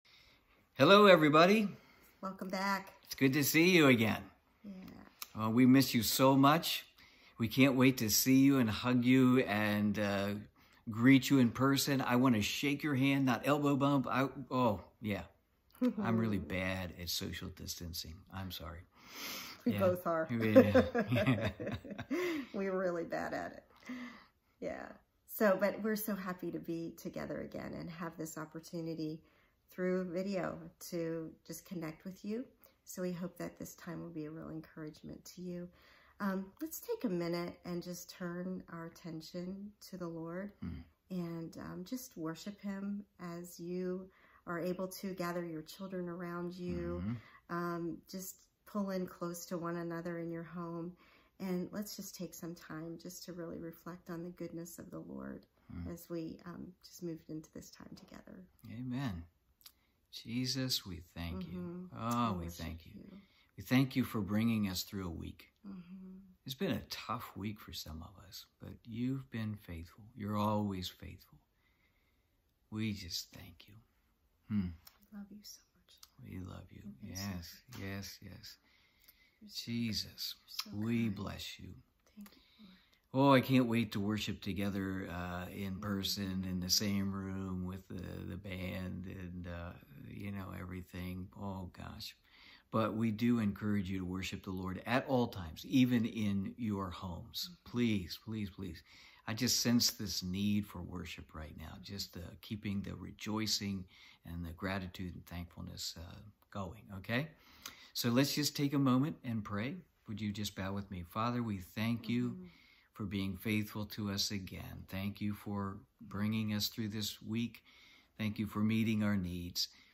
Sunday morning online service